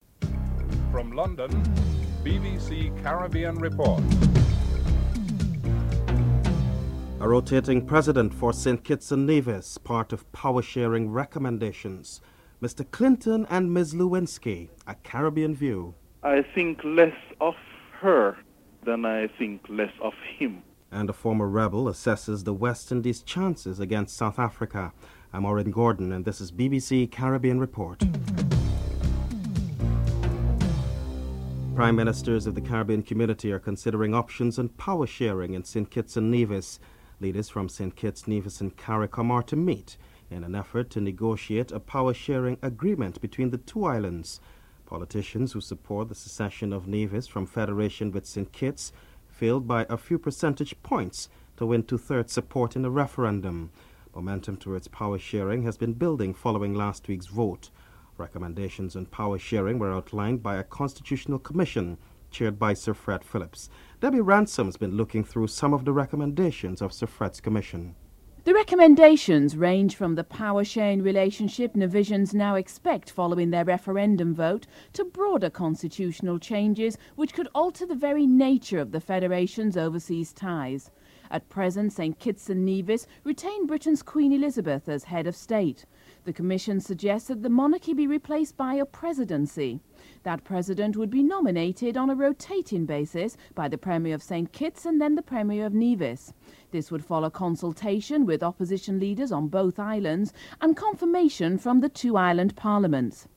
1. Headlines (00:00-00:31)
Caribbean people are interviewed (09:00-11:02)